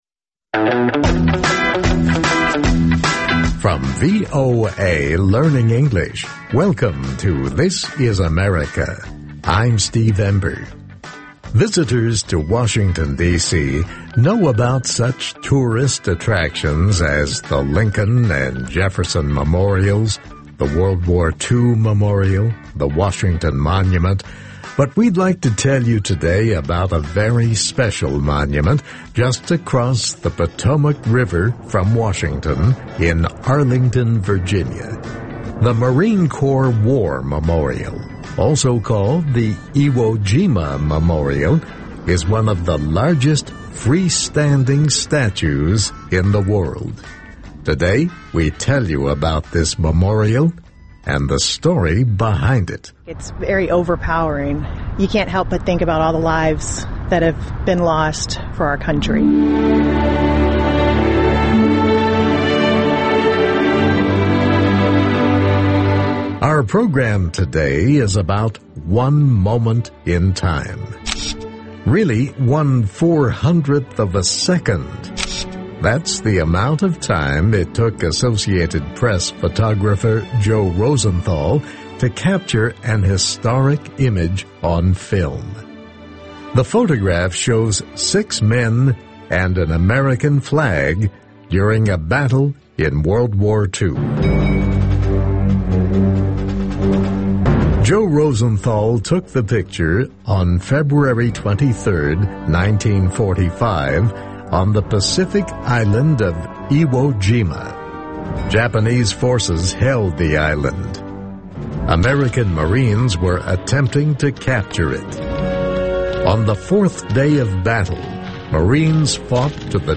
ინგლისური ენის გაკვეთილები ("ამერიკის ხმის" გადაცემა)